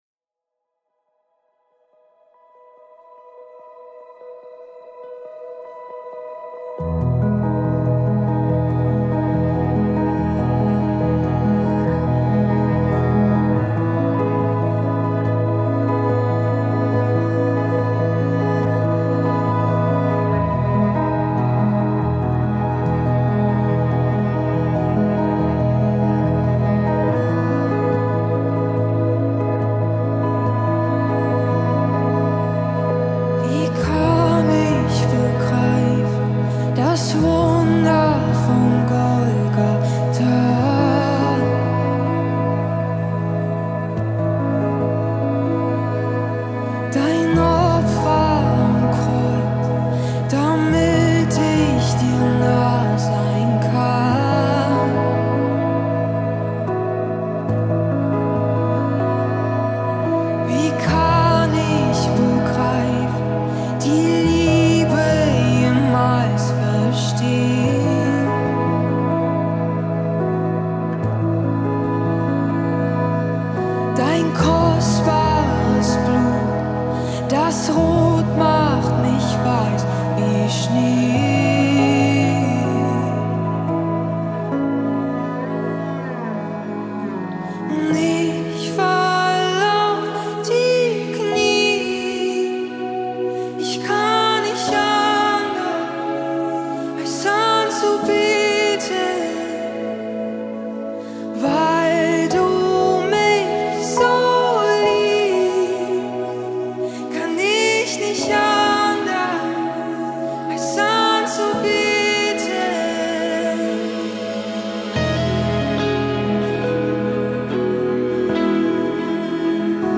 184 просмотра 70 прослушиваний 4 скачивания BPM: 70